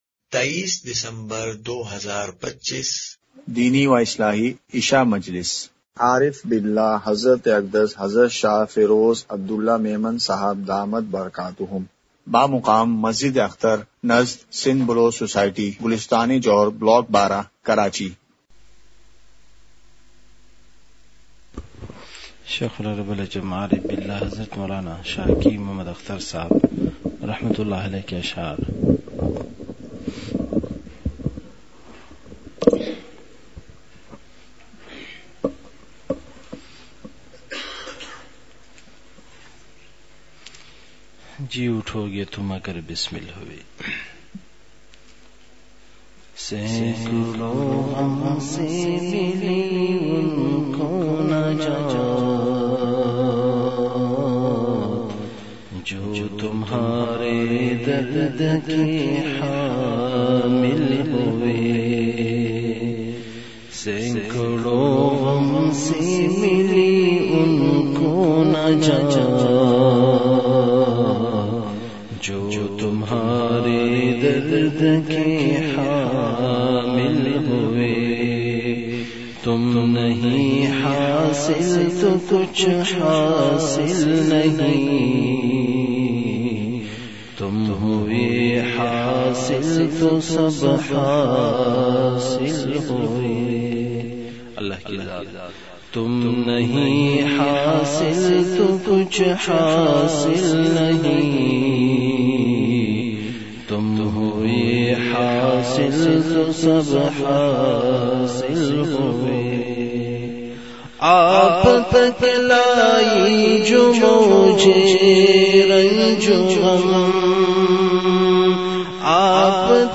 مقام:مسجد اختر نزد سندھ بلوچ سوسائٹی گلستانِ جوہر کراچی
08:44) بیان کے آغاز میں اشعار پڑھے گئے۔۔